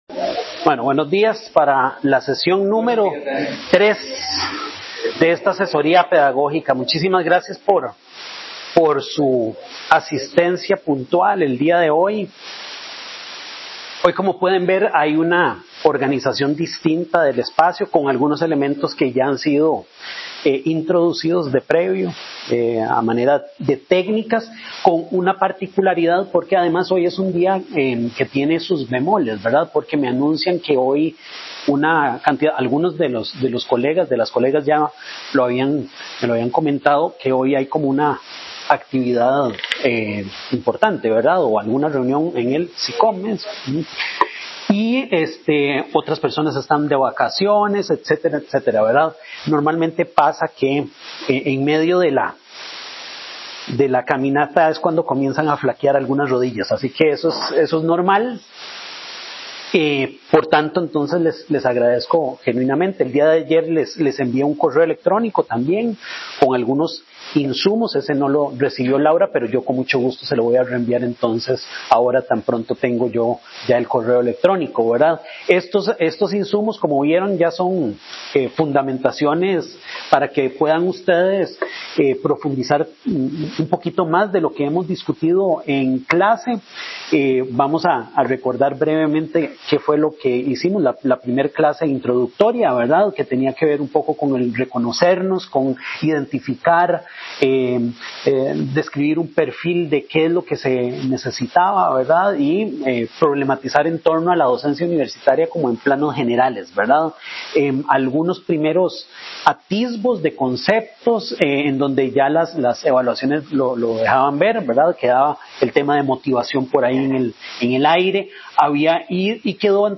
La estrategia para atender la necesidad del público asincrónico fue grabar parcialmente la sesión para su beneficio, manteniendo algunas partes sin grabar para preservar la naturaleza efímera y genuina de la discusión presencial.